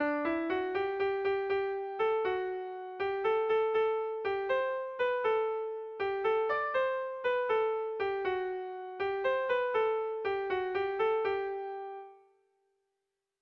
Kontakizunezkoa
ABDE